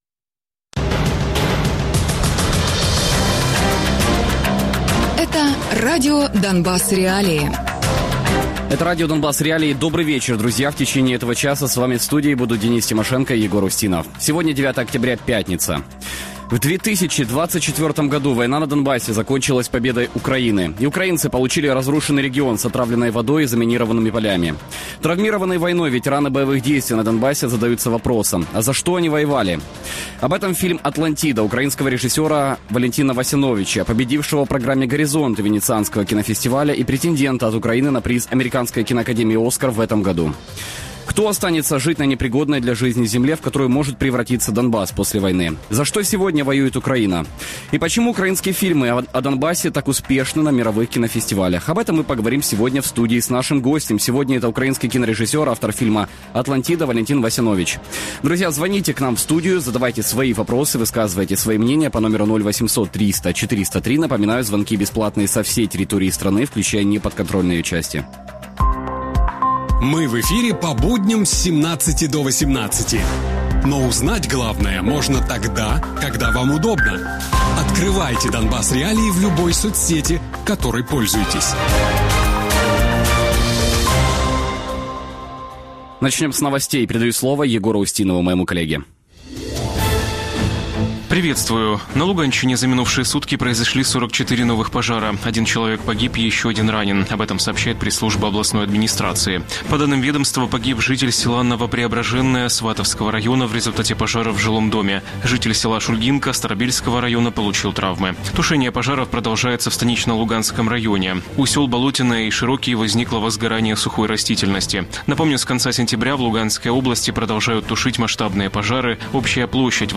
Гість програми: Валентин Васянович - український кінорежисер, автор фільму «Атлантида».